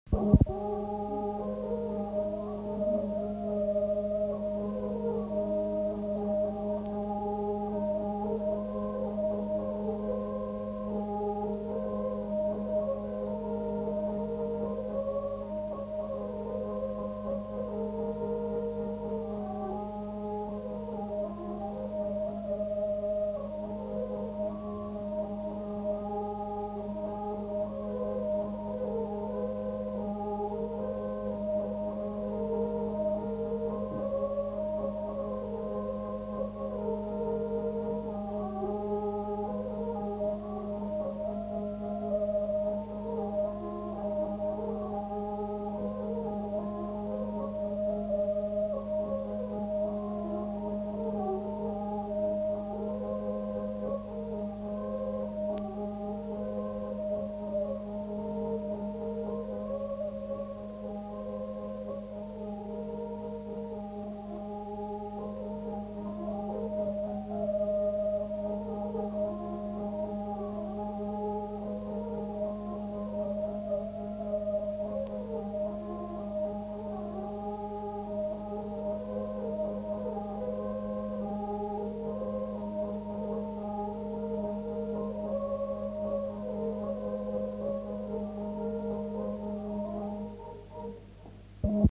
Lieu : Villeneuve-de-Marsan
Genre : morceau instrumental
Instrument de musique : vielle à roue
Danse : valse